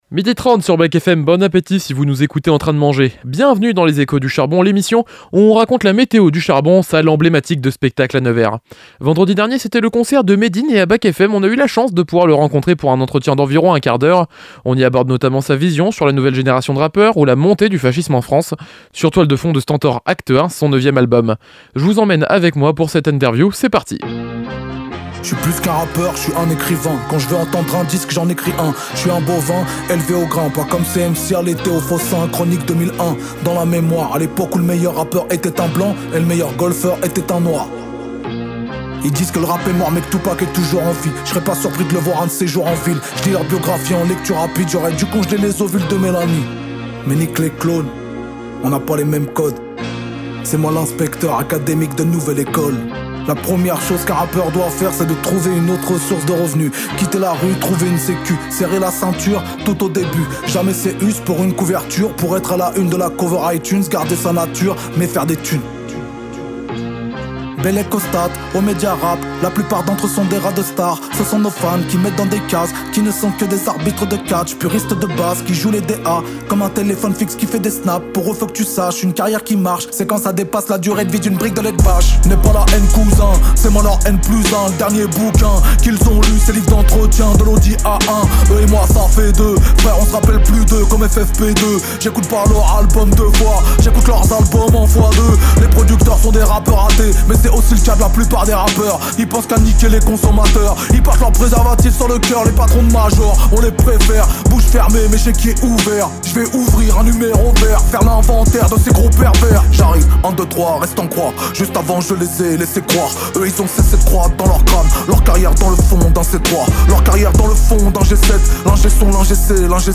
Interview de Médine, rappeur engagé, avant son concert au Café Charbon à Nevers le vendredi 21 novembre 2025 dans le cadre de la tournée de promo de son dernier album STENTOR, act 1 paru au printemps dernier